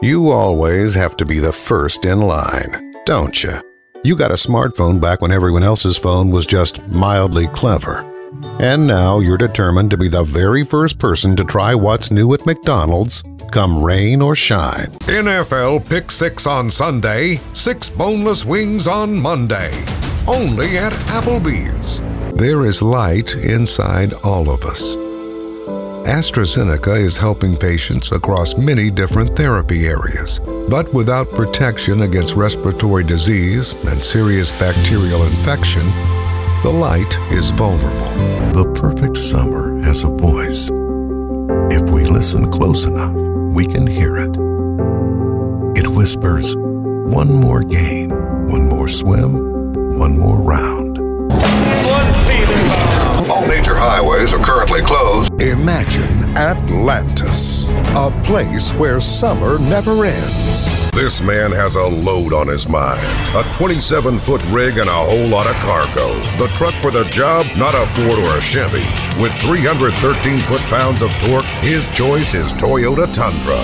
Gravitas. Comedy. Narration.
Showreel